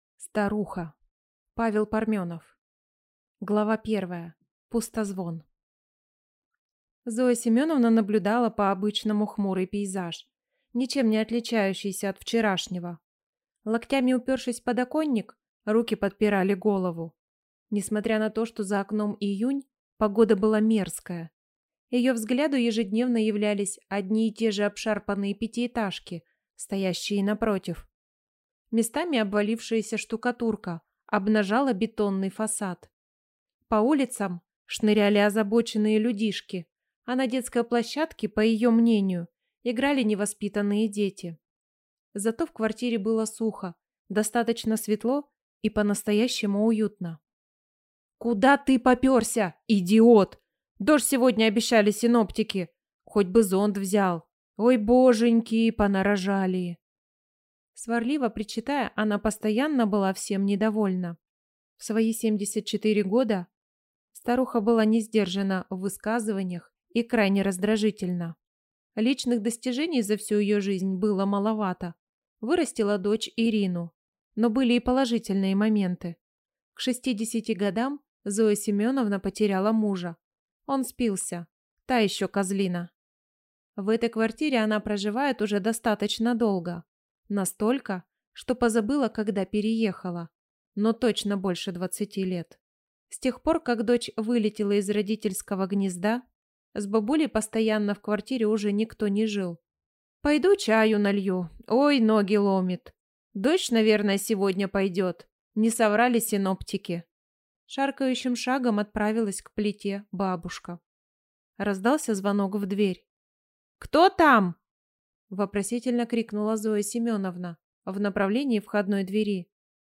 Аудиокнига Старуха | Библиотека аудиокниг
Прослушать и бесплатно скачать фрагмент аудиокниги